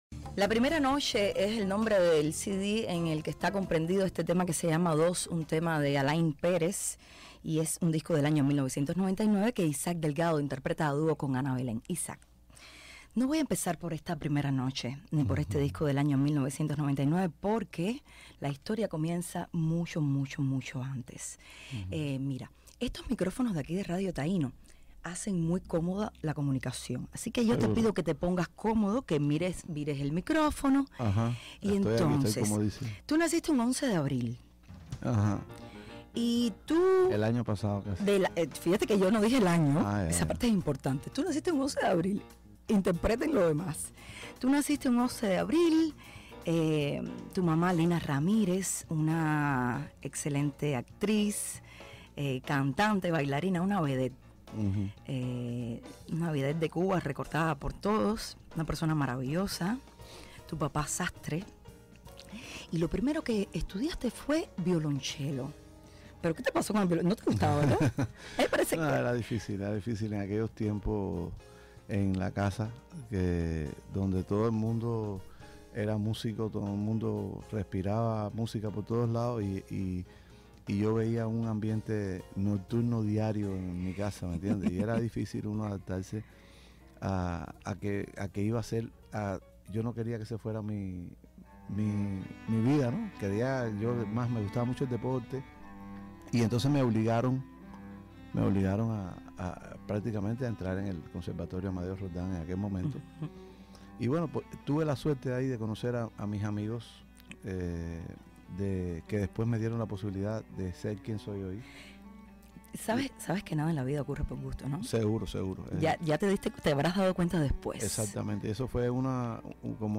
Интервью классика кубинской танцевальной музыки Иссака Дельгадо в эфире радио "Таино", Куба, 2016 г. Переведено и опубликовано для вас!